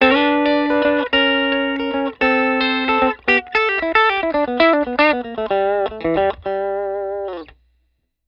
TELE-BRIDGE-LICK-3-ML4.wav